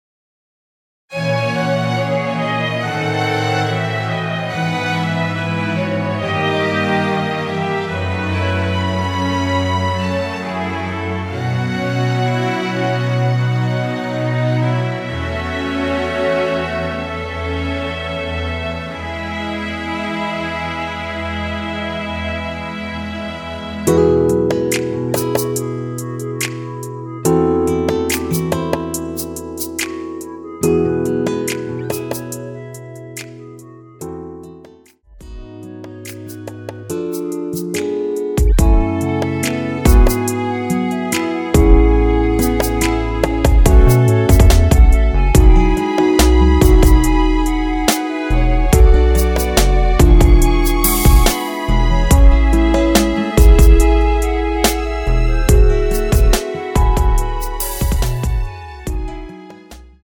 원키 멜로디 포함된 MR입니다.
주 멜로디만 제작되어 있으며 화음 라인 멜로디는 포함되어 있지 않습니다.(미리듣기 참조)
앞부분30초, 뒷부분30초씩 편집해서 올려 드리고 있습니다.
중간에 음이 끈어지고 다시 나오는 이유는